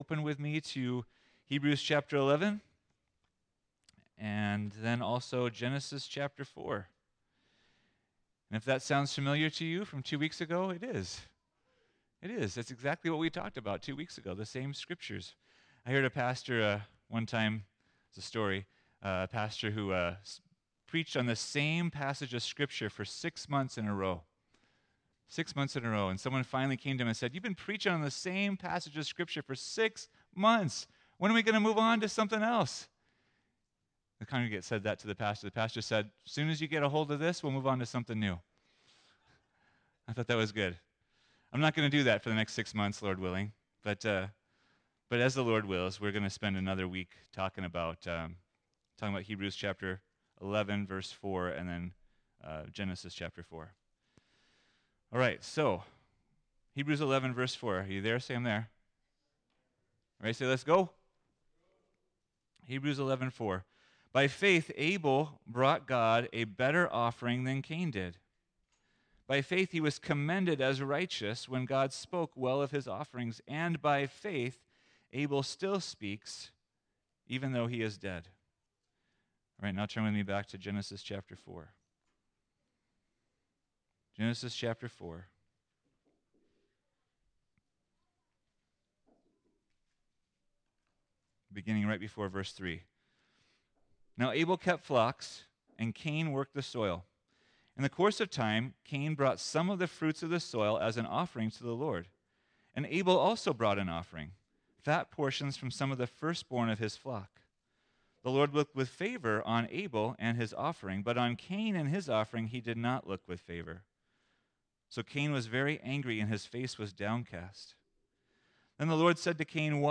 Offenders Preacher